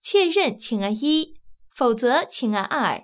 ivr-one_yes_two_no.wav